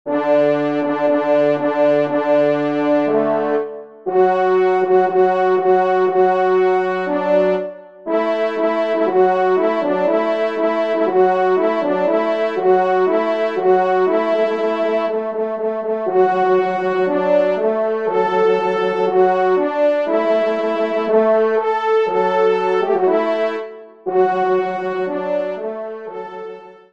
4ème Trompe